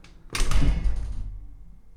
Window Aluminum Close Sound
household
Window Aluminum Close